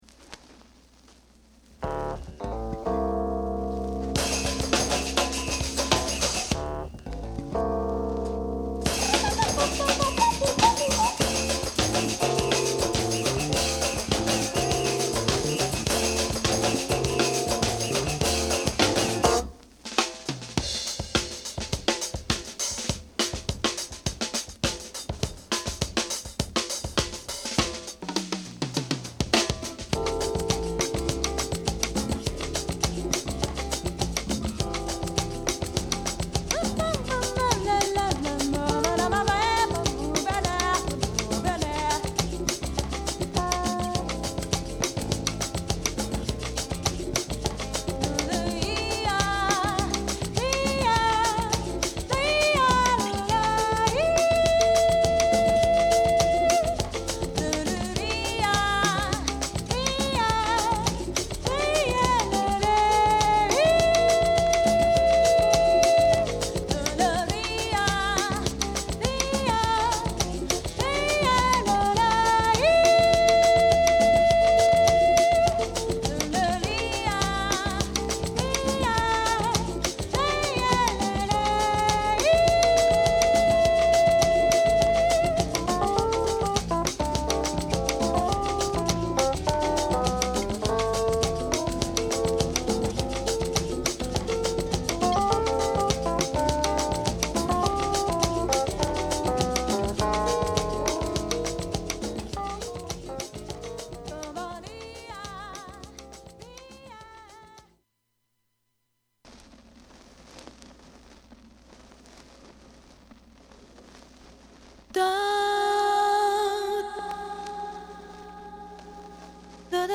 DANCE / SOUL / HOUSE / LATIN